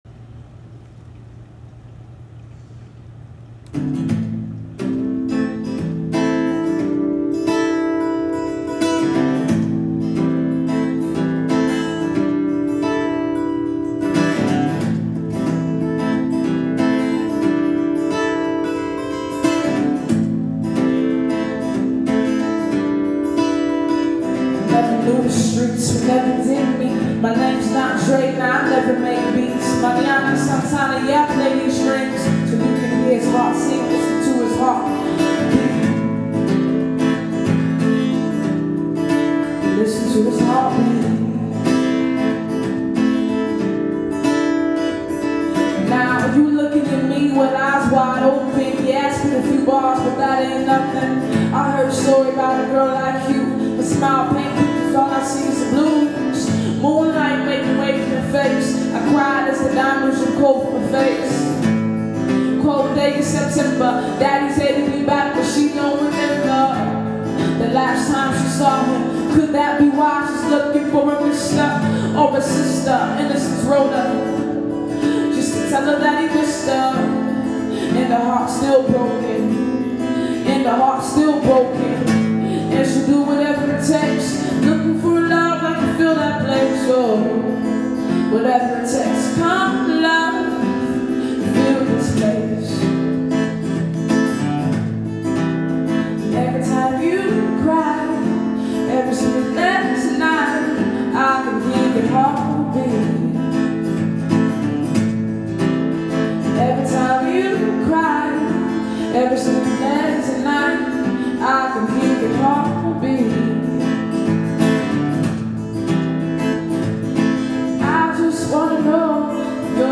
Live acoustic performance of the new song